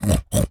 pig_sniff_06.wav